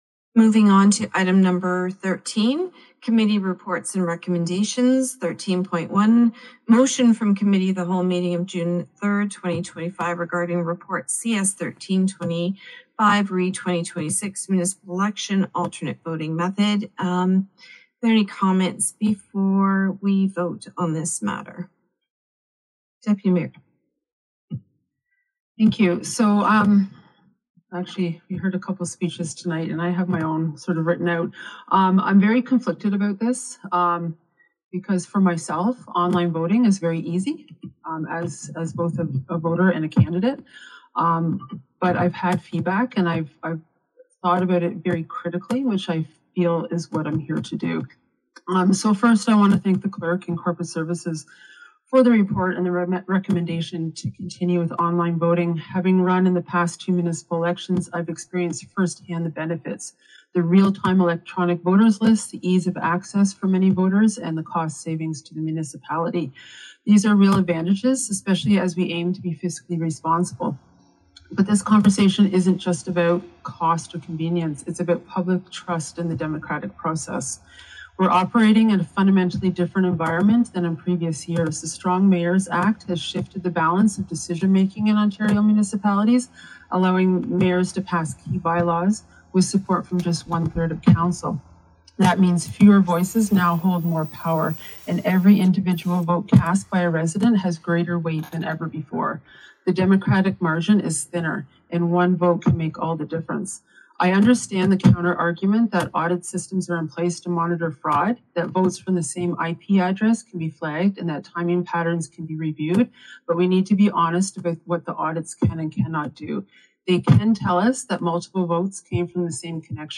Port Hope council debated changes to its municipal election process at its regular council meeting on June 17.
Listen to hear the politicians weigh in as the debate unfolds.